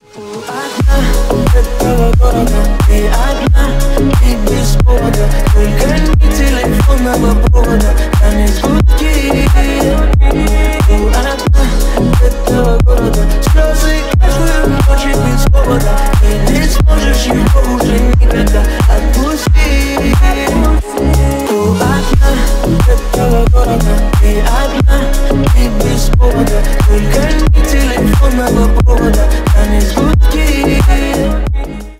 Ремикс # Поп Музыка
клубные # грустные